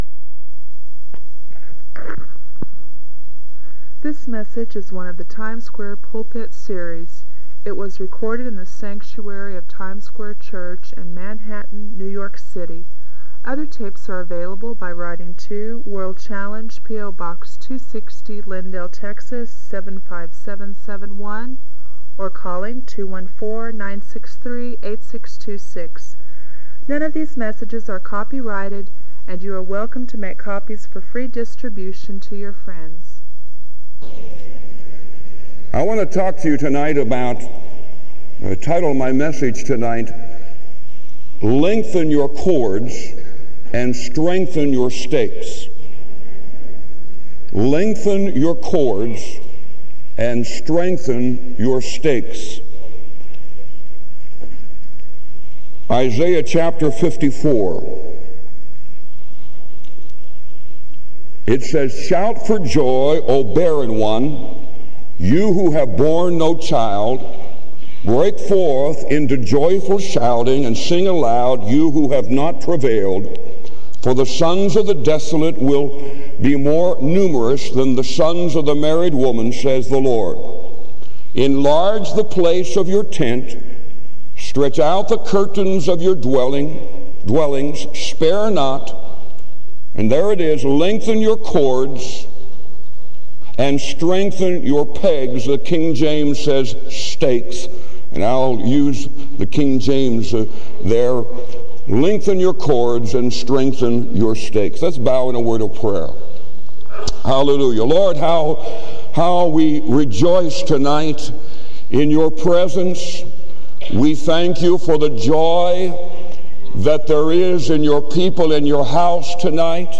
This sermon is a call to both personal and corporate spiritual growth.